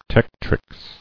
[tec·trix]